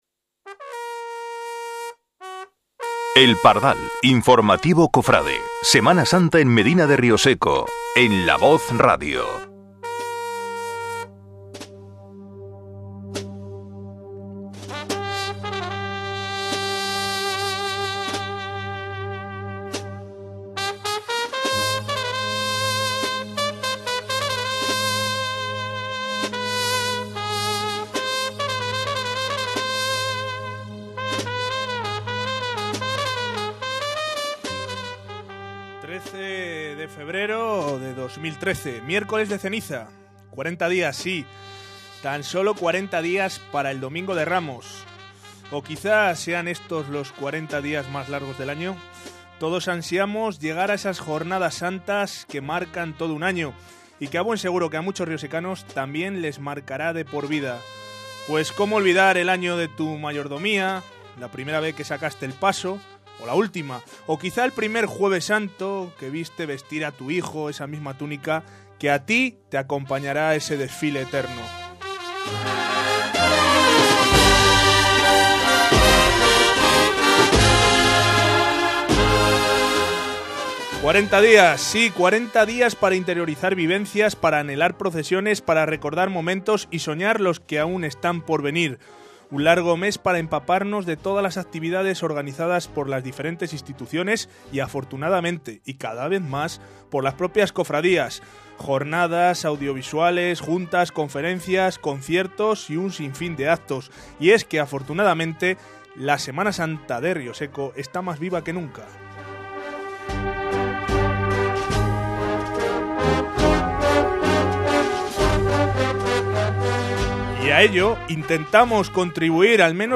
¿Qué mejor día para hacer un programa radiofónico sobre Semana Santa? Así empezó el segundo capítulo de la temporada de El Pardal. Informativo Cofrade, el programa semanasantero de La Voz Radio.